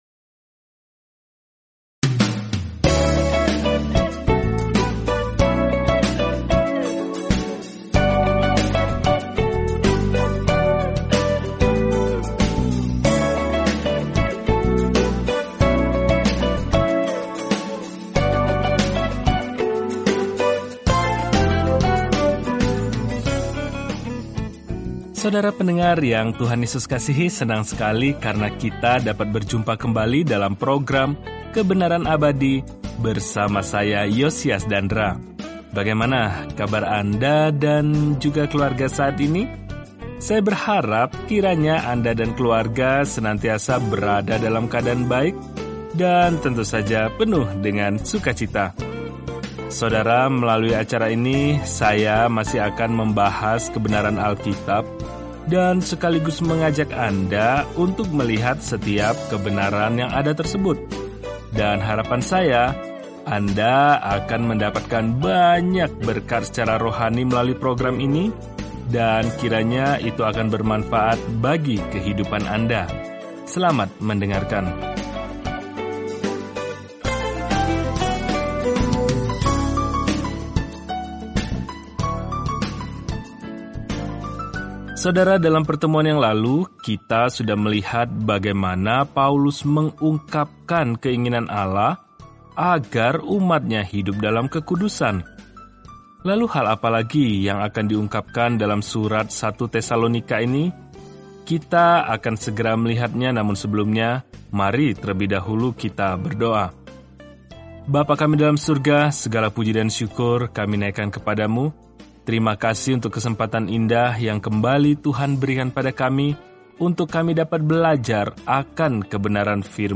Jelajahi 1 Tesalonika setiap hari sambil mendengarkan pelajaran audio dan membaca ayat-ayat tertentu dari firman Tuhan.